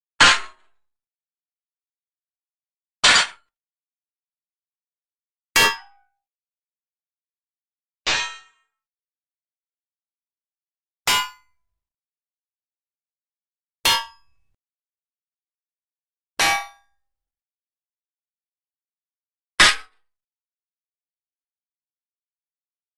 1. Звук удара пули о броню n2. Как звучит пуля, попадая в броню n3. Звук пробития пулей брони n4. Пуля встречает броню – звук столкновения n5. Характерный звук попадания пули в бронеплиту n6. Звонкий удар пули по броне n7. Как звучит пробитие брони пулей